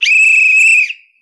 whistle